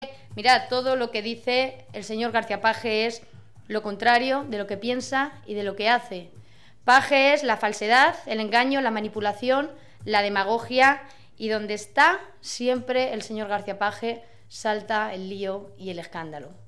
En rueda de prensa, Agudo ha recordado que el PP es el partido que ha ganado las elecciones en la región en número de votos, número de escaños y número de concejales, así como la formación que más mayorías absolutas y relativas ha obtenido.